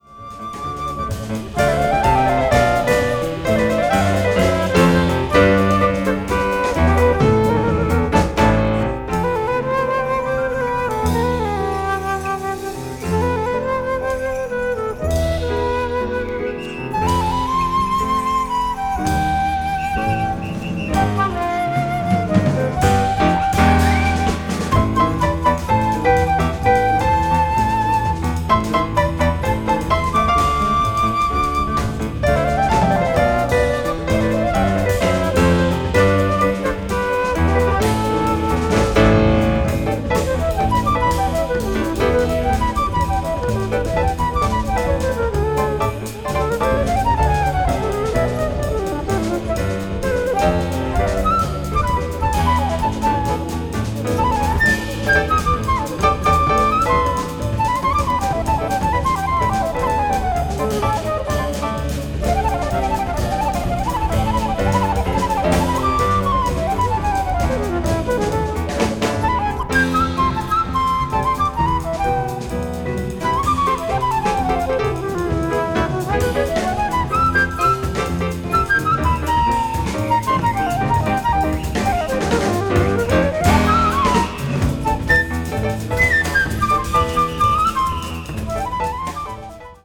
media : EX-/EX-(薄いスリキズによるわずかなチリノイズが入る箇所あり)